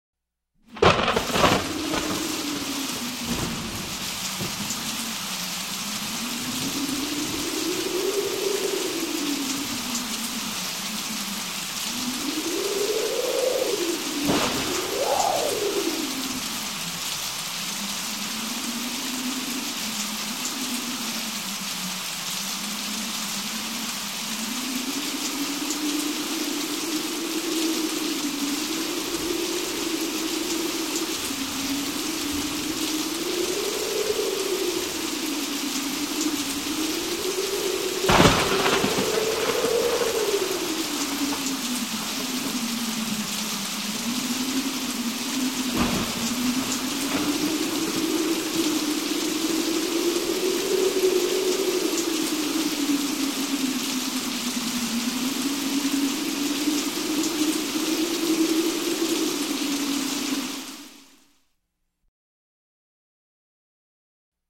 Звуки грома, грозы
Звуки воя ветра, раскатов грома, шума ливня